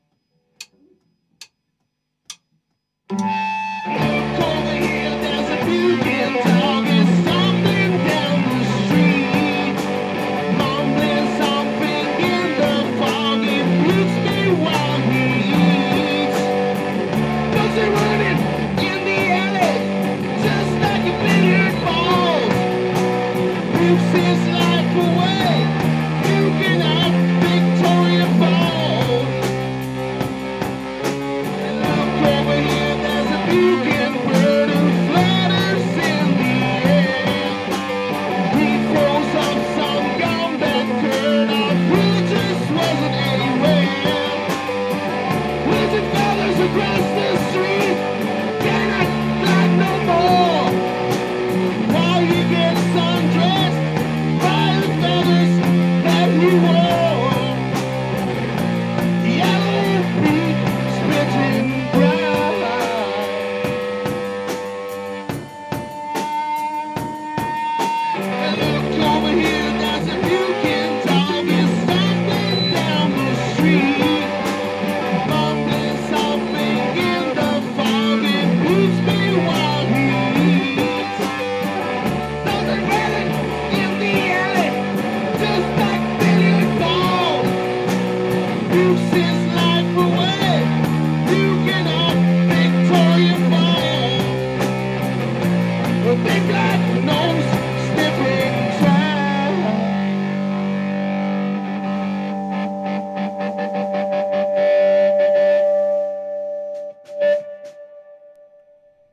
Un suono ruvido e verace, carico di flanella e sudore